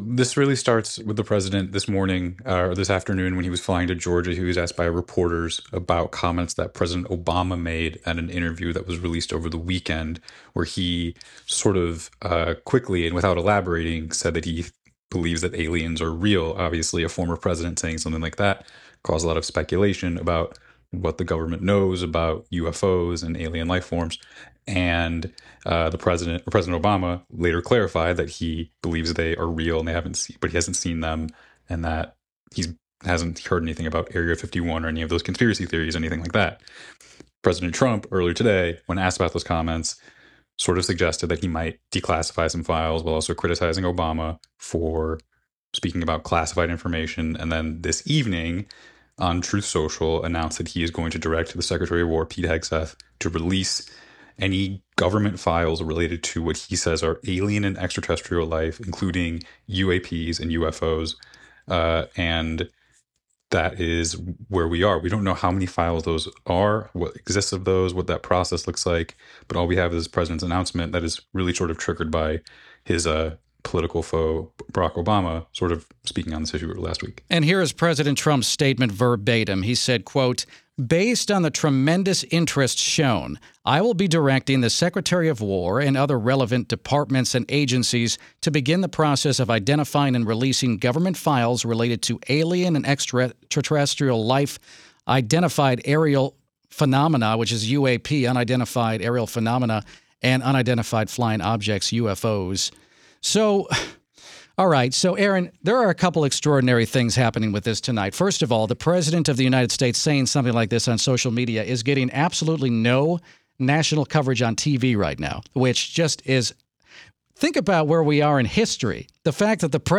joins WTOP to discuss President Trump's decision to release classified files on UFOs